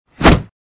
knife_slash1.mp3